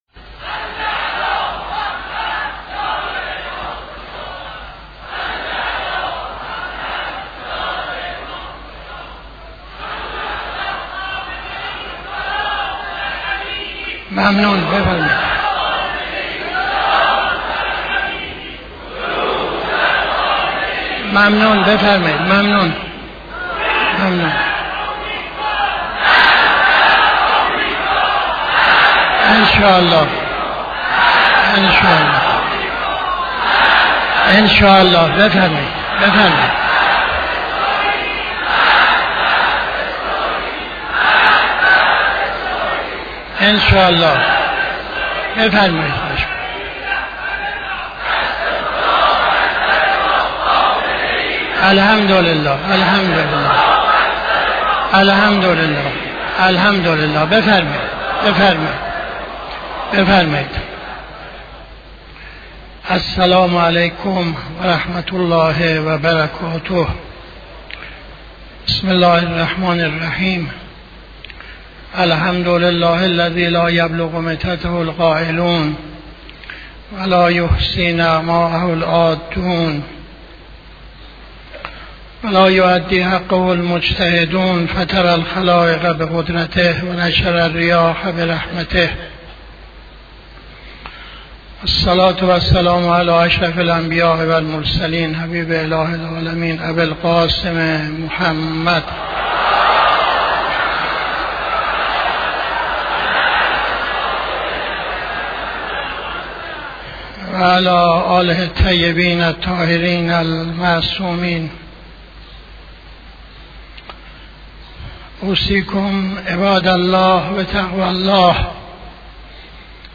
خطبه اول نماز جمعه 26-12-79